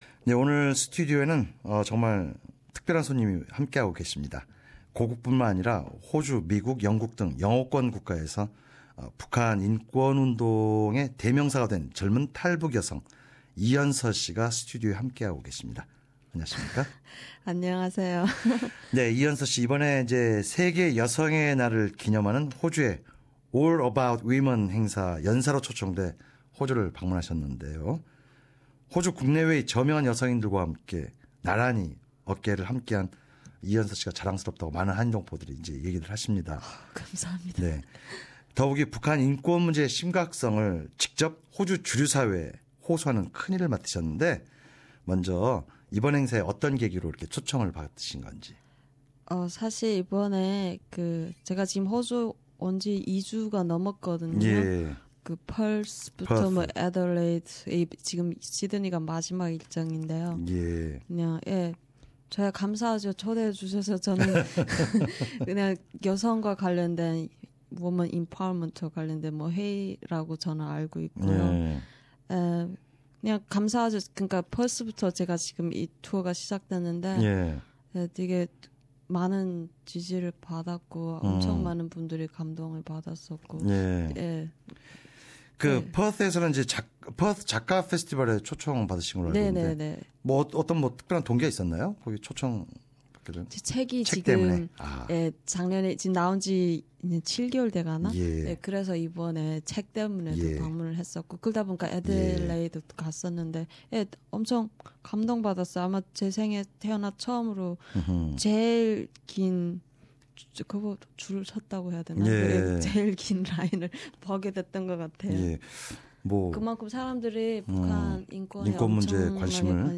North Korean defector and activist Hyeonseo Lee at SBS studio Source: SBS